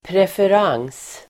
Uttal: [prefer'ang:s]